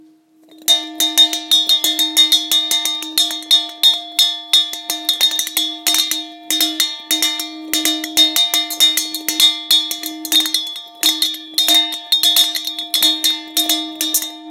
Zvonček na zavesenie biely 15x21cm